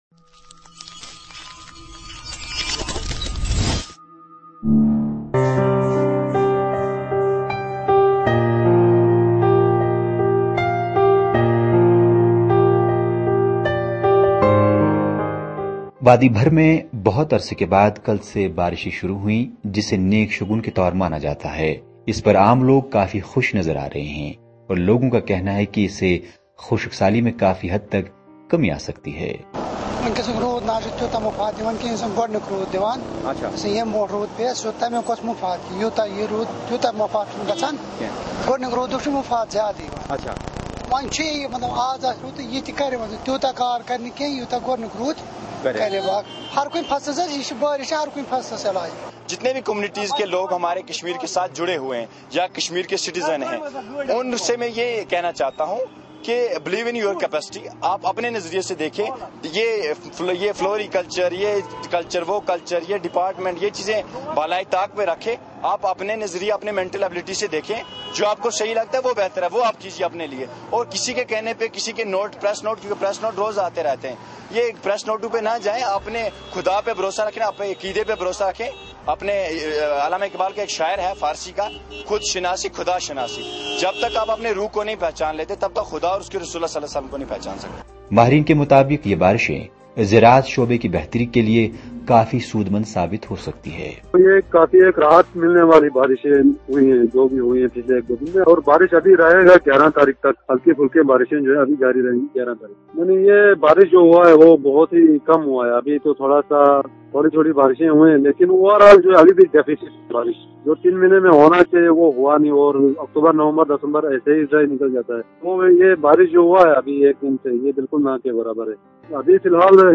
اب کل رات سے بارشیں ہونے کو ایک نیک شگون کے طور مانا جاتاہے۔اس حوالے سے جب خبر اردو نے عام لوگوں سے بات کی تو وہ کافی خوش نظر آرہے ہیں ۔